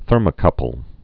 (thûrmə-kŭpəl)